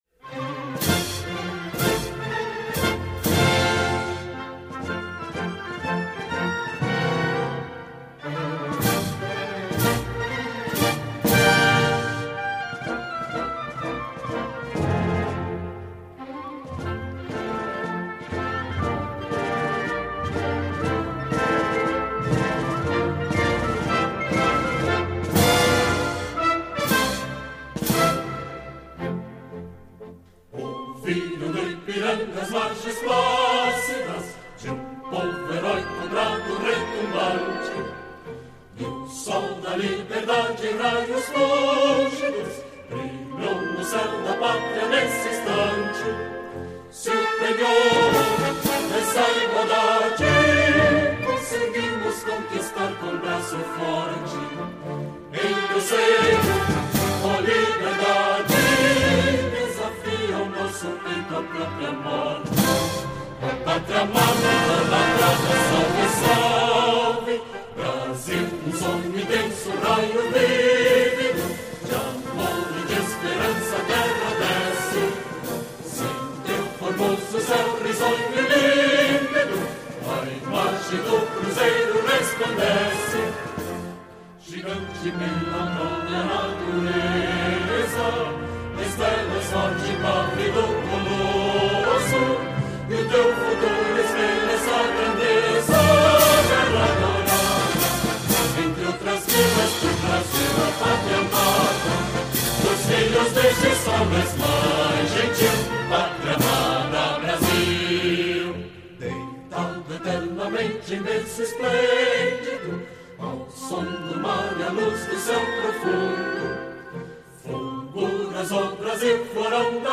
Вдохновенное исполнение гимна